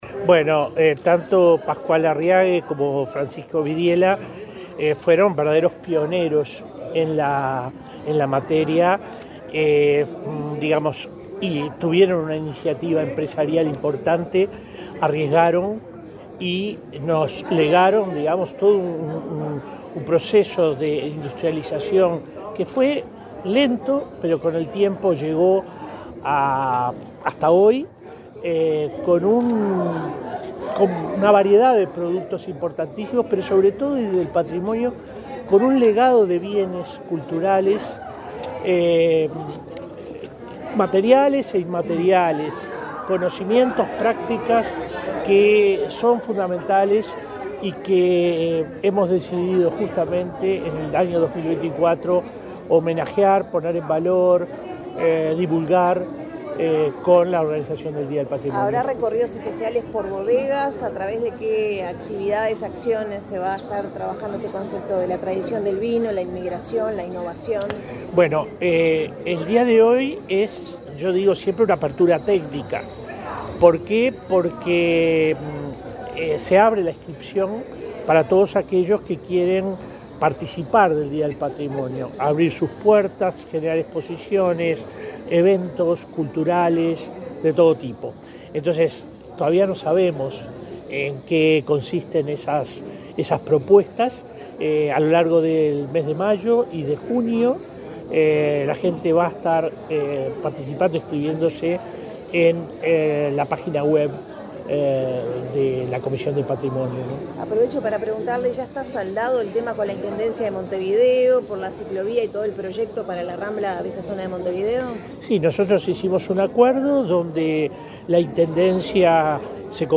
Declaraciones del director general de la Comisión de Patrimonio, William Rey
El director general de la Comisión de Patrimonio, William Rey, dialogó con la prensa, luego de participar en la presentación del Día del Patrimonio